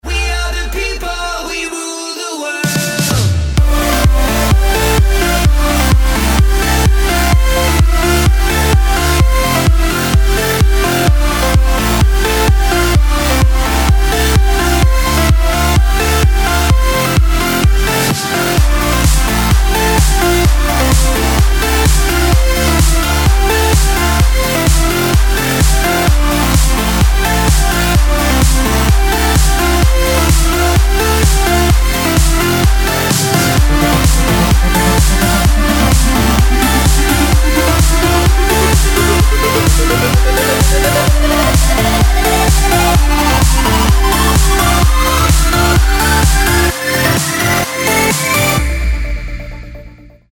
• Качество: 192, Stereo
Попробуй усиди под такую музыку!